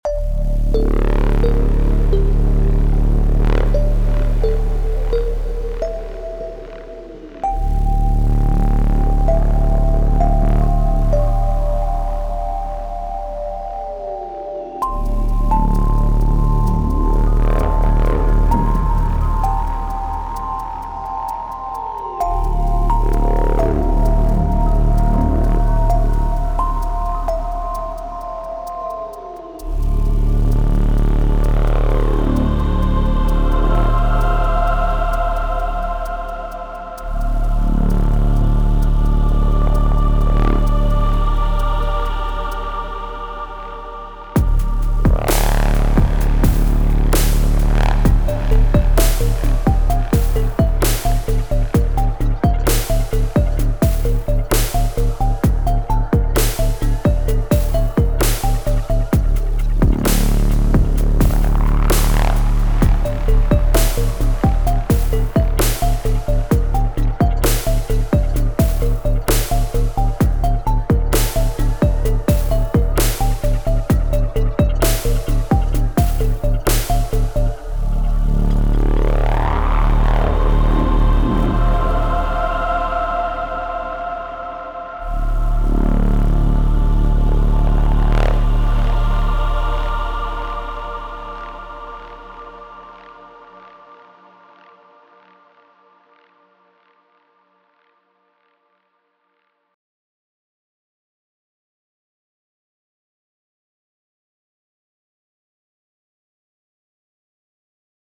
A little thing made with Pivot, all sounds except choirs is the synth.
I really like sounds like the main droning bass, some movement a feeling like its holding back a little. Im no sound design genious so I used the reese bass preset and went from there :smiley: